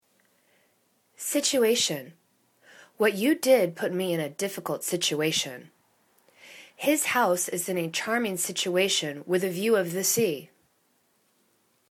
sit.u.a.tion /sichw'a:shәn/ [C]